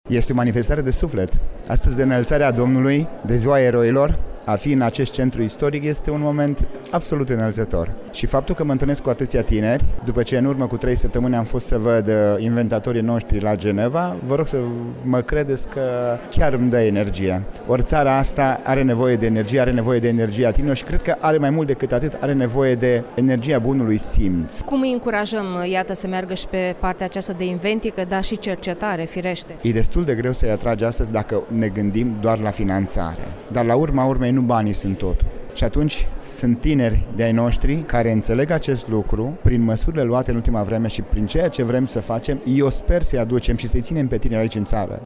La eveniment participă și ministrul Cercetării, Nicolae Burnete, care a declarat pentru Radio Iași că va încuraja tinerii să rămână în țară și va susține cercetarea românească.